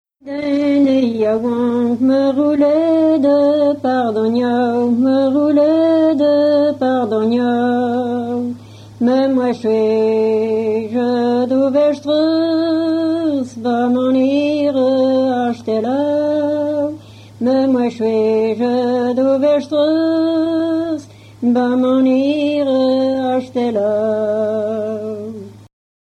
Origine : Centre-Bretagne Année de l'arrangement : 2011